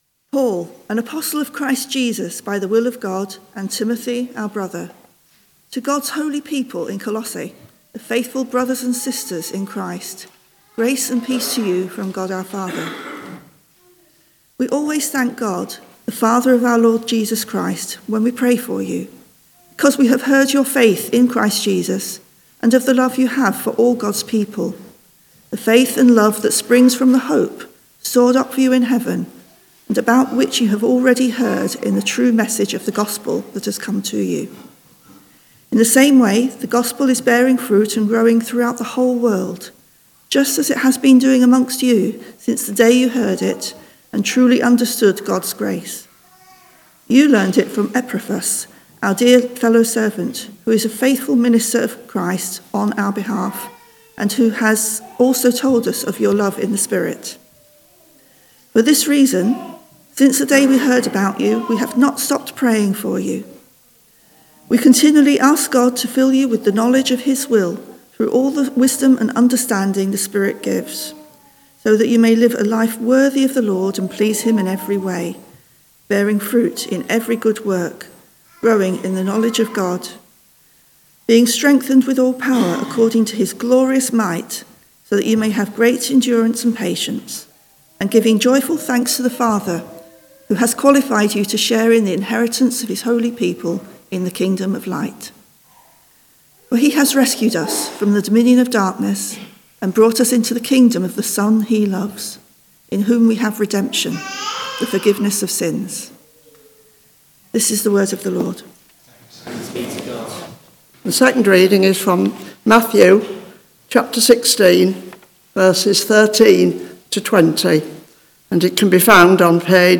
Media for Morning Service on Sun 09th Feb 2025 10:45 Speaker
Rooted Theme: Christ the Cornerstone Sermon Search